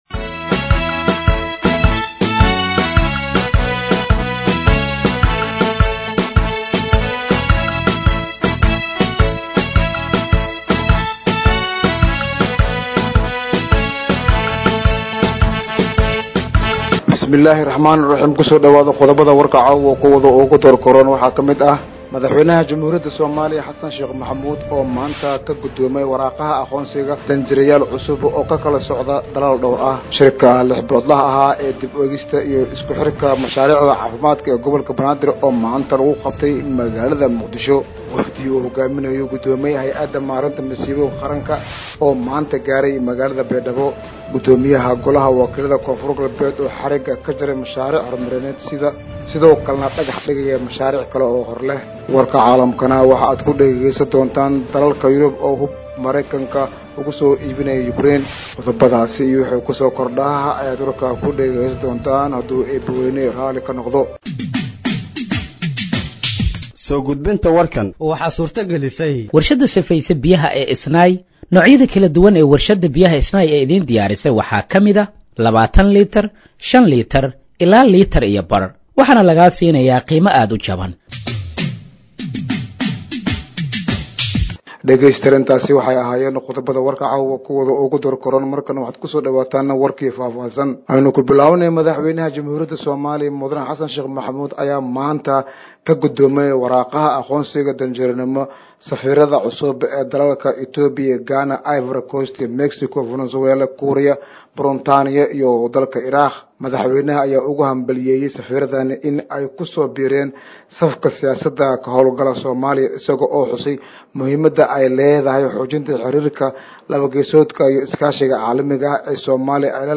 Dhageeyso Warka Habeenimo ee Radiojowhar 05/08/2025